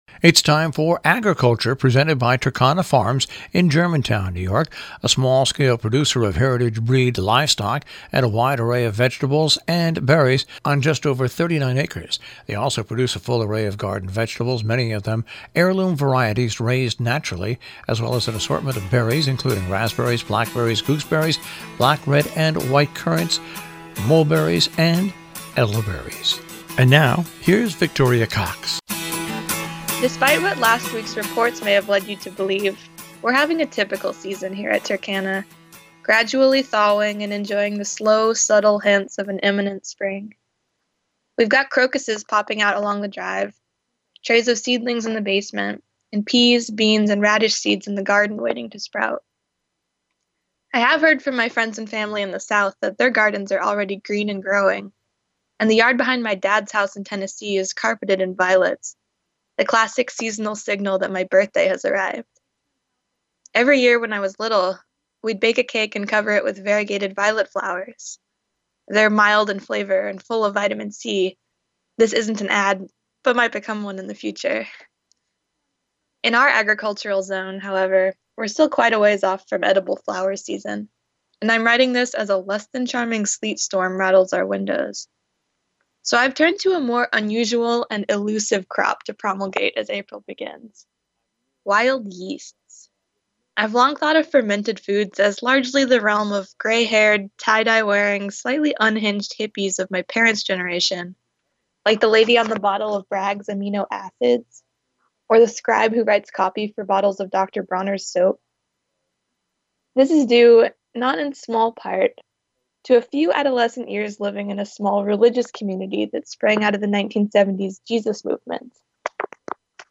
HEAR OUR SHOWIf you'd enjoy hearing these bulletins out loud instead of reading them, we broadcast them on Robin Hood Radio, the nation's smallest NPR station.